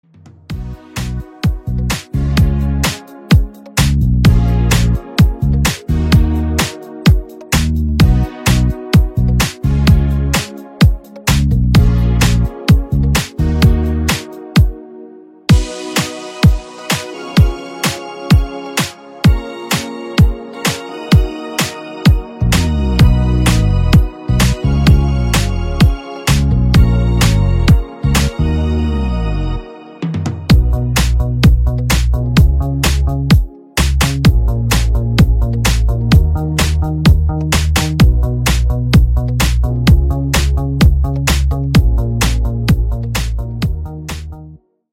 Electrónica